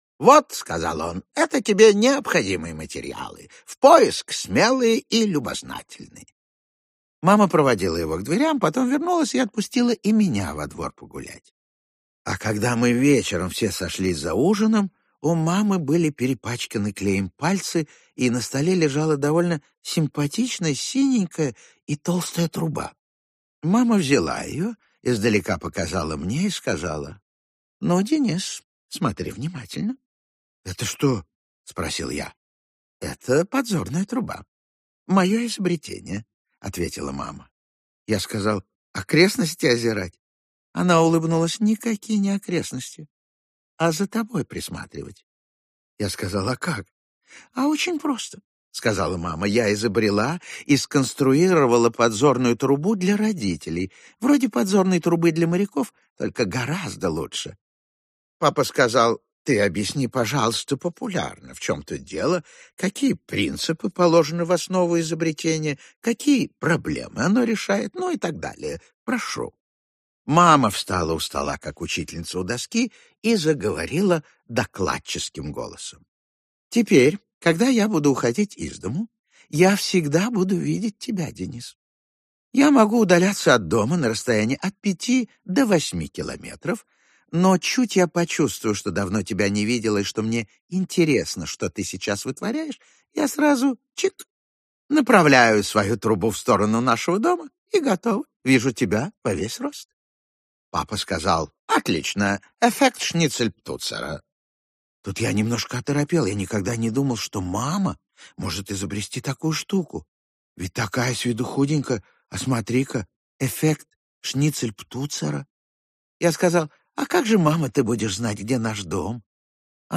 Аудиокнига Денискины рассказы. Подзорная труба | Библиотека аудиокниг